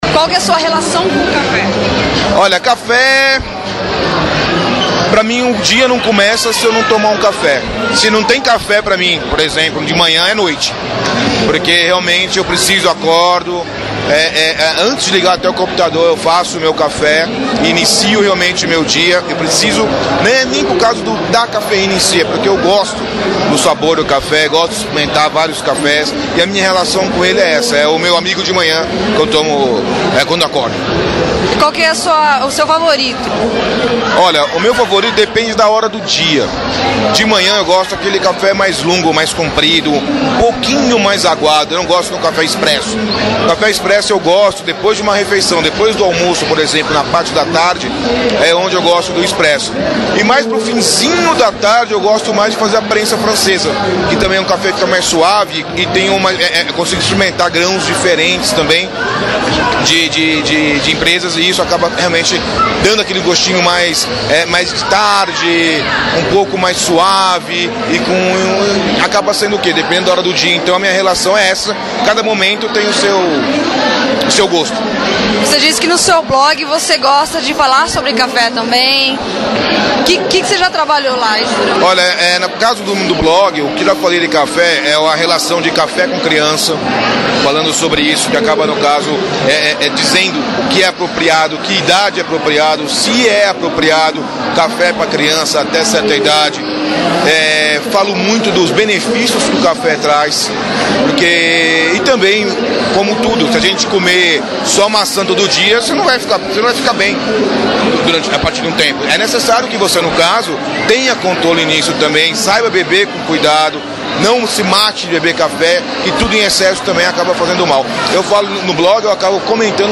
Café no YouPix Festival – Nerd Pai